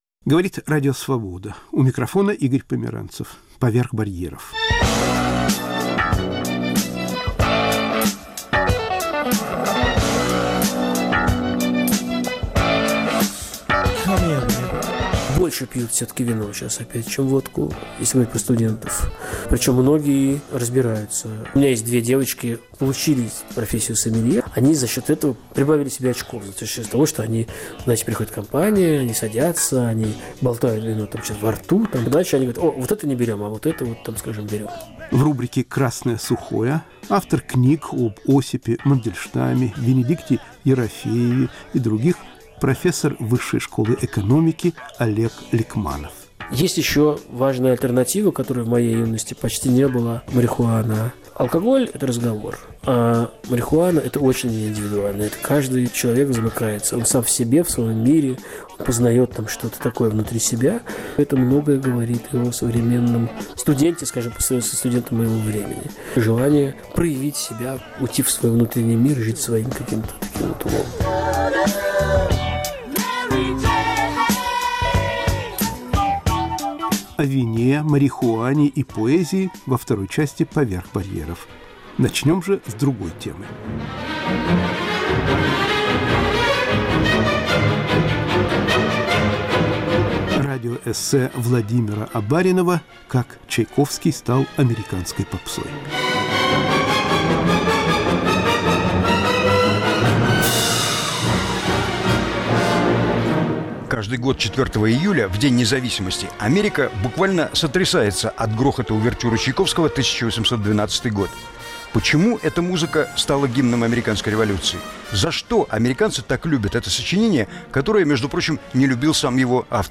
Как Чайковский стал американской попсой (радиоэссе).